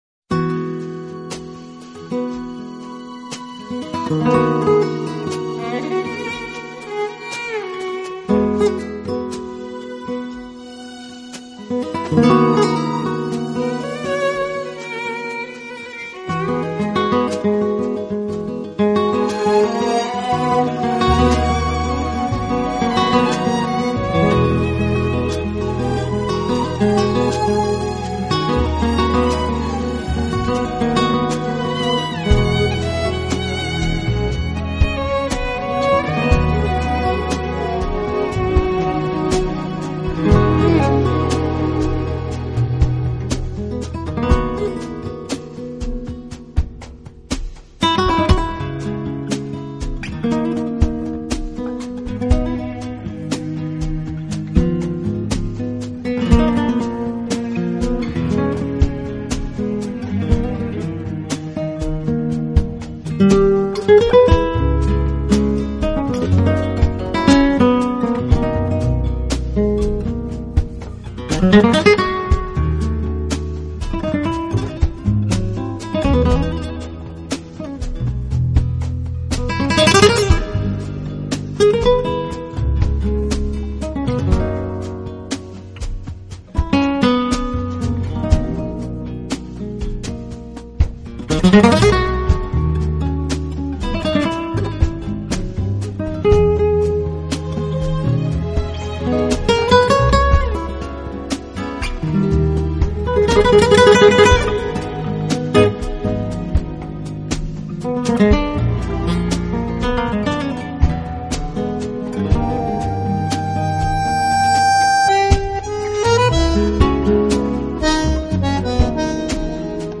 【吉他专辑】